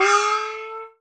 CHINA FX.wav